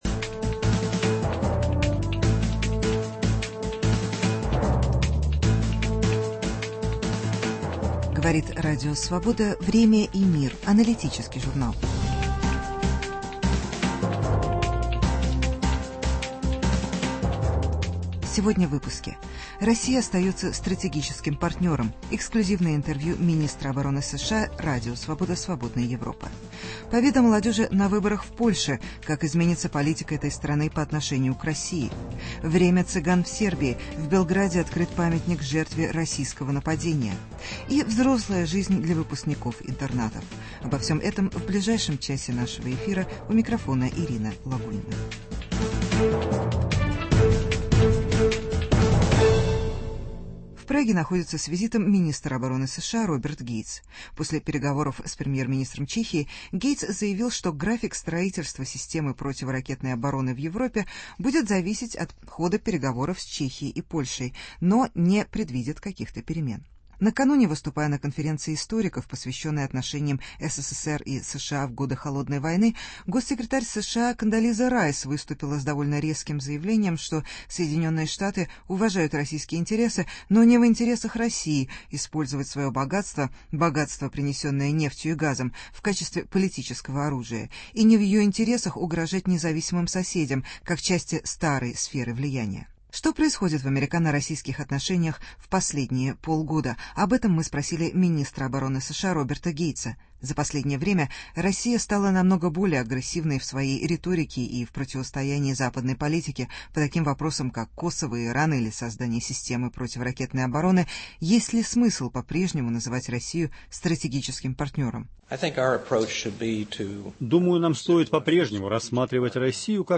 Интервью с министром обороны США Робертом Гейтсом. Победа молодежи на выборах в Польше. Памятник цыганскому мальчику в Белграде (разговор о положении рома в Сербии).